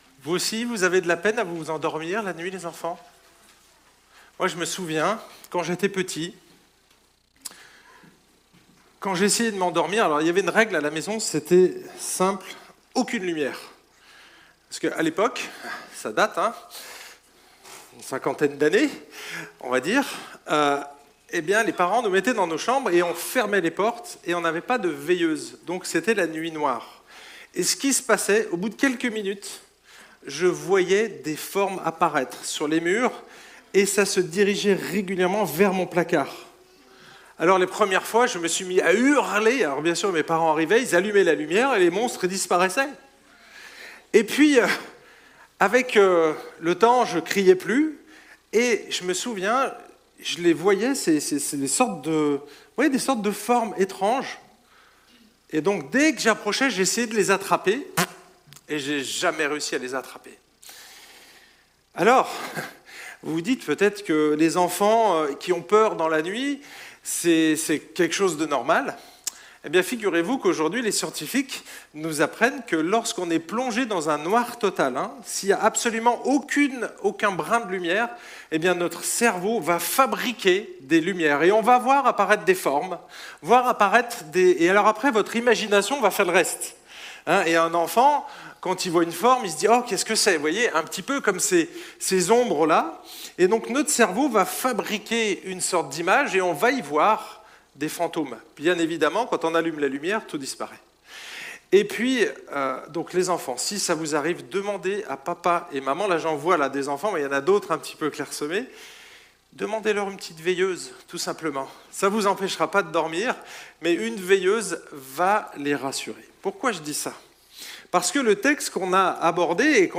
Culte spécial Noël